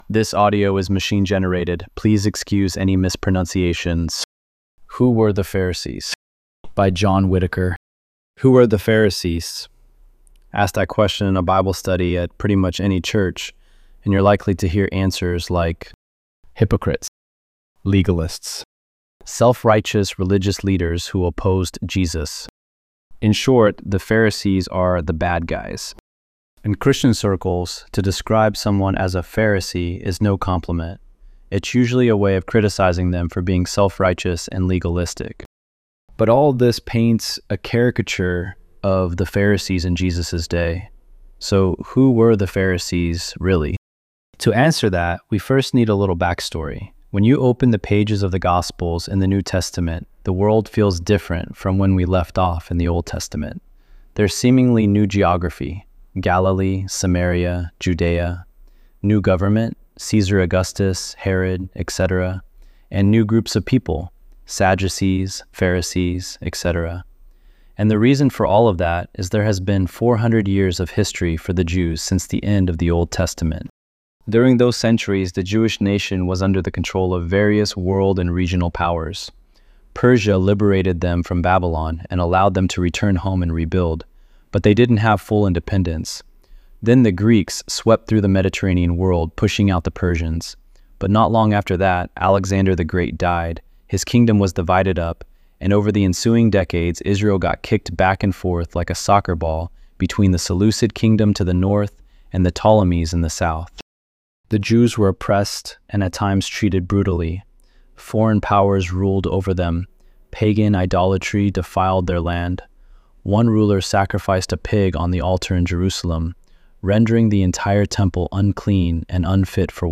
ElevenLabs_3_13.mp3